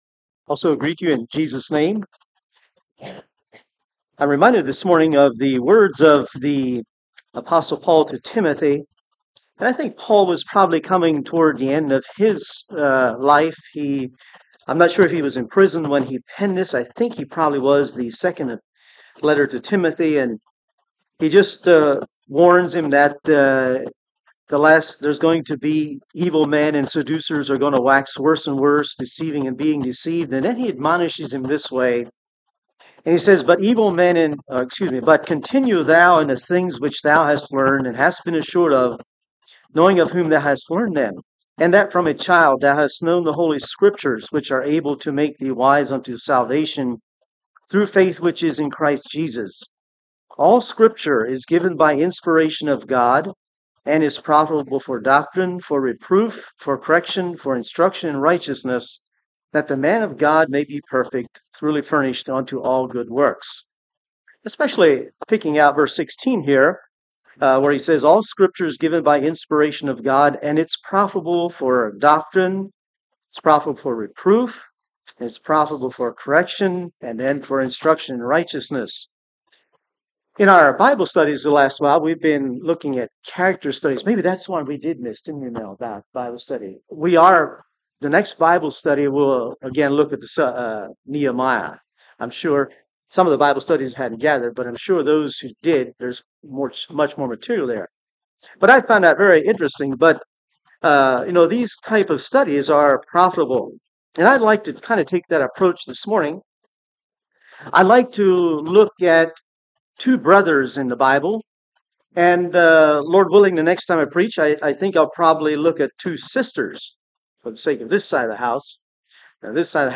Passage: Luke 15 Service Type: Sunday Morning Topics: Parable , The Lost Son « Ye Have Need of Patience